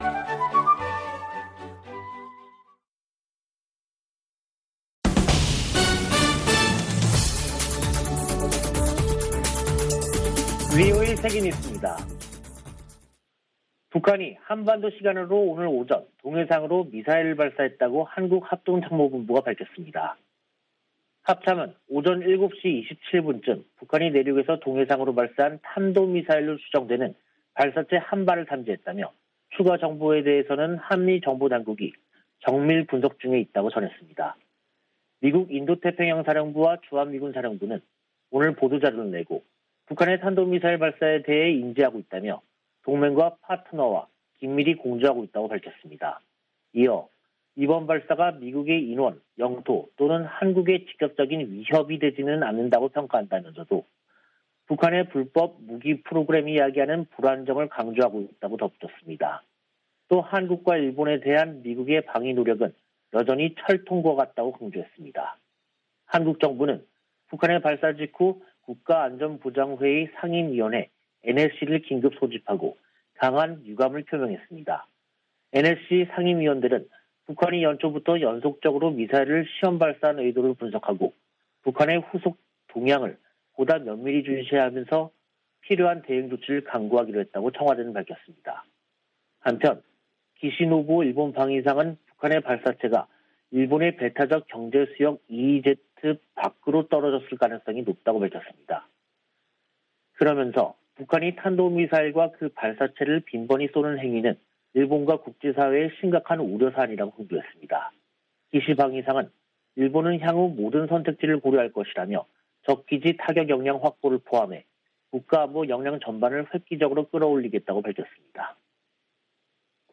VOA 한국어 간판 뉴스 프로그램 '뉴스 투데이', 2022년 1월 11일 2부 방송입니다. 북한이 엿새 만에 또 다시 탄도미사일 추정체를 발사했습니다. 유엔 안보리가 지난 5일의 북한 탄도미사일 발사 문제를 논의했습니다. 미 국방부는 극초음속 미사일을 발사했다는 북한의 주장에 세부사항을 평가 중이라고 밝혔습니다.